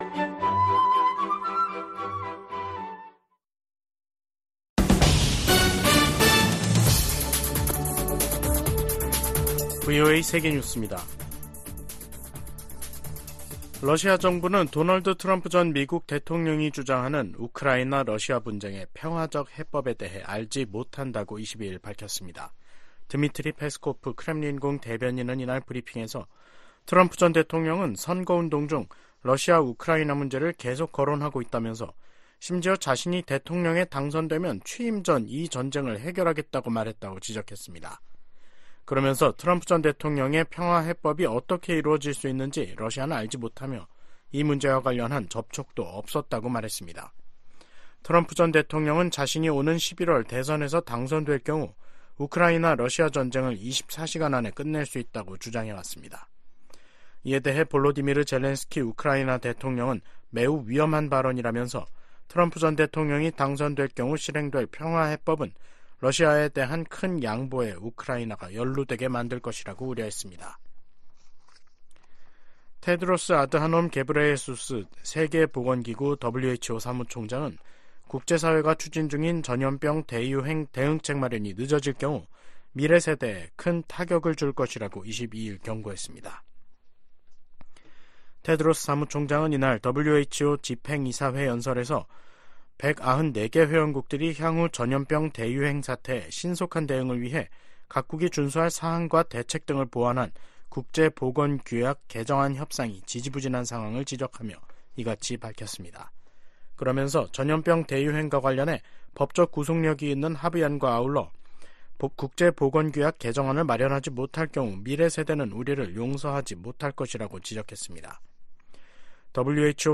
VOA 한국어 간판 뉴스 프로그램 '뉴스 투데이', 2024년 1월 22일 3부 방송입니다. 미 국무부가 북한의 수중 핵무기 시험 주장에 도발을 중단하고 대화에 나서라고 촉구했습니다. 백악관은 수중 핵무기 시험 주장과 관련해 북한이 첨단 군사 능력을 계속 추구하고 있음을 보여준다고 지적했습니다.